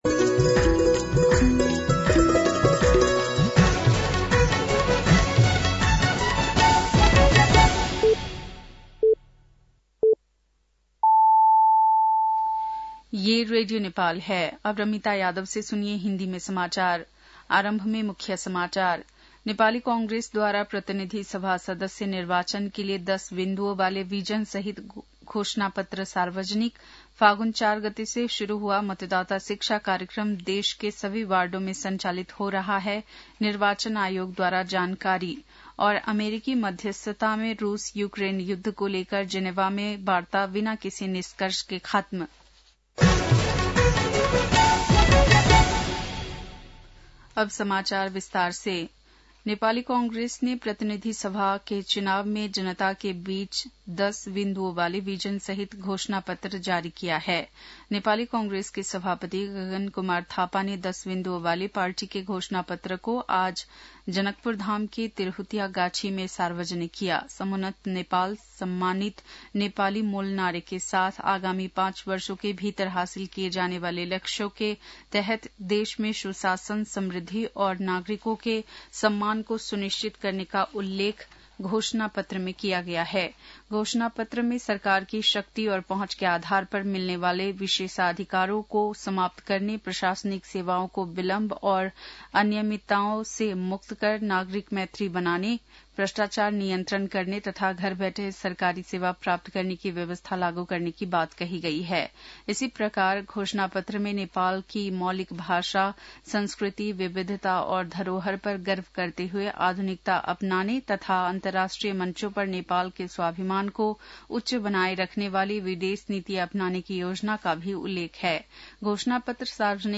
बेलुकी १० बजेको हिन्दी समाचार : ६ फागुन , २०८२